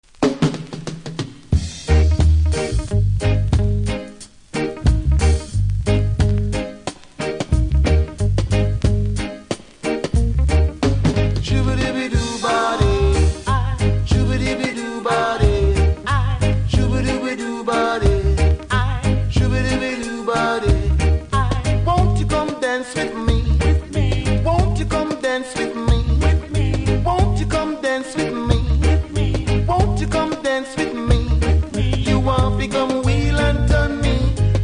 キズは多めですが音はそれほどでも無いので試聴で確認下さい。